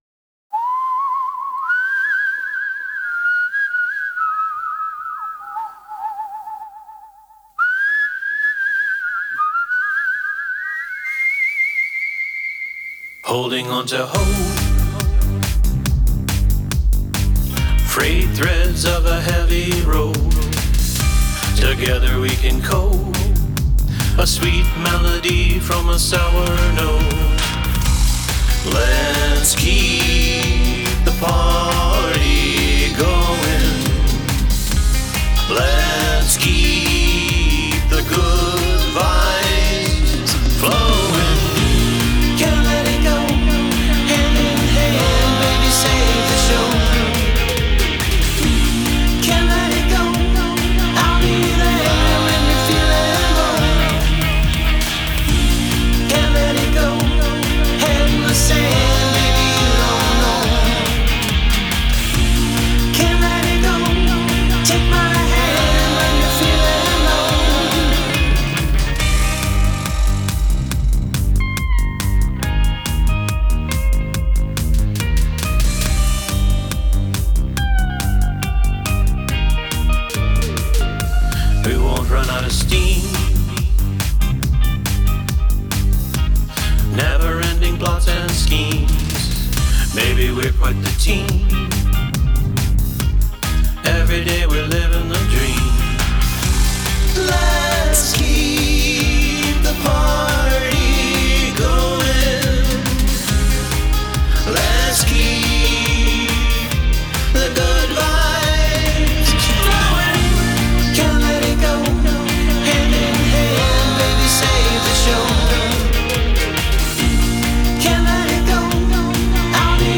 Must include a guest from outside the band singing a vocal harmony
really like the chorus on this one, and the layers of guest vocals work really well. Lots of nice ear candy, that pitch bend into the bridge is great.
Bass is a little farty, which isn't an issue until it's exposed at 1:10 or so. 80's toms sprayed everywhere.
I like the chorus melody a lot and there's a lot of fun ear candy going on in the production--I enjoyed the layers of lush backing vocals, the ultra-80's drums, echoey guitar (the lead stuff in the bridge is really nice), vibraslap, etc. all add up to fun and excellent ear candy. The slide up into the bridge is great.
Some nice melodies and I'm always a sucker for counterpoint vocals and I think I would enjoy this song if you played it to me on a guitar or piano but the Europop production is such a stumbling block for me here, I'm afraid.